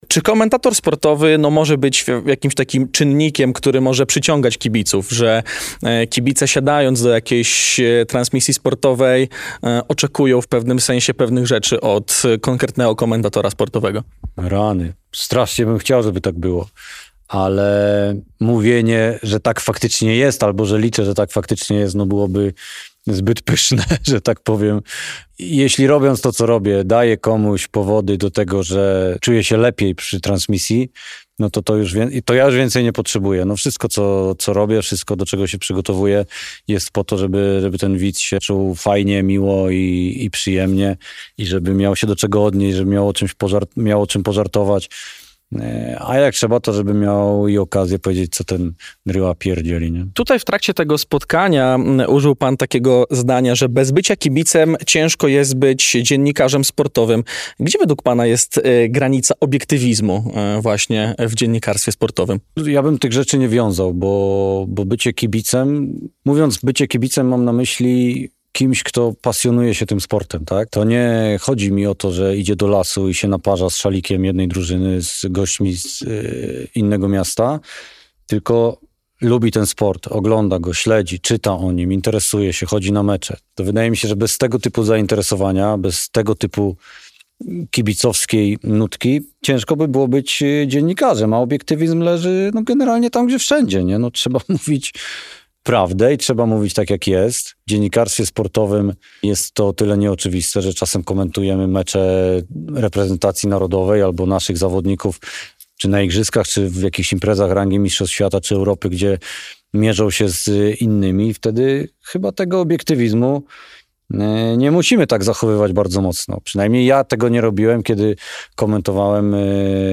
Na wiele różnych tematów porozmawialiśmy z nim przy okazji spotkania ze studentami jakie odbyło się na wydziale Politologii i Dziennikarstwa UMCS.